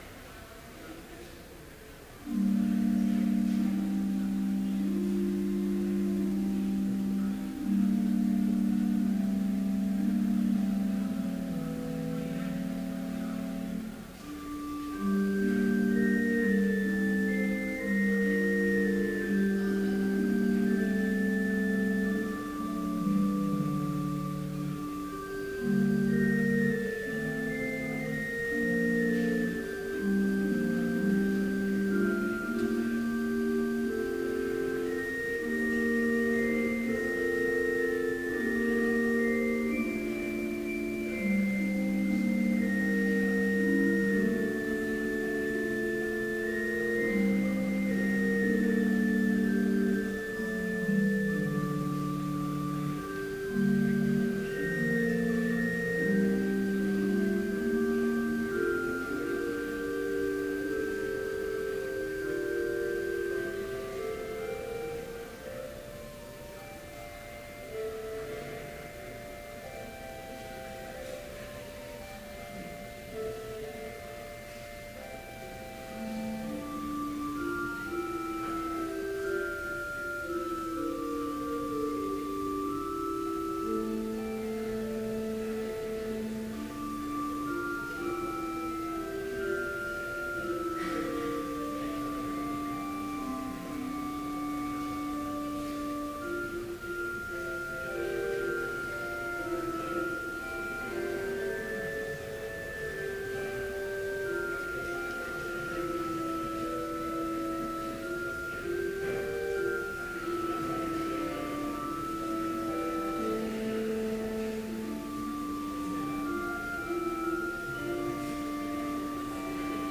Complete service audio for Chapel - March 25, 2015